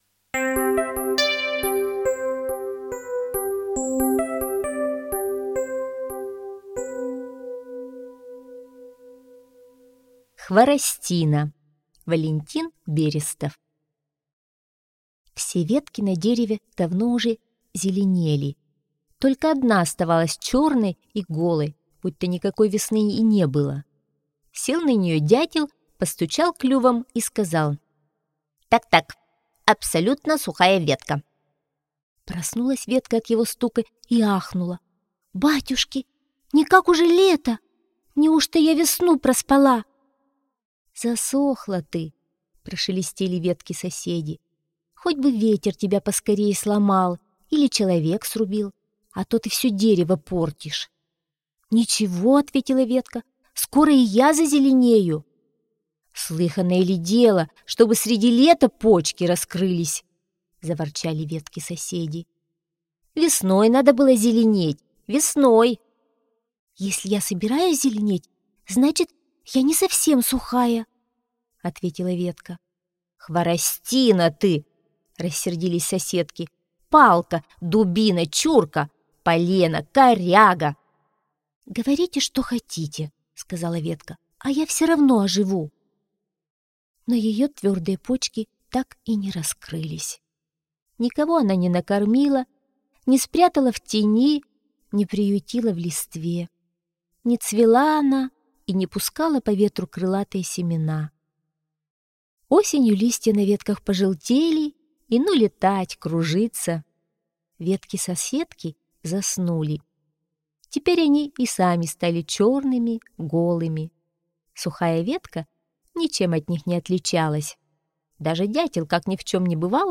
Аудиосказка «Хворостина»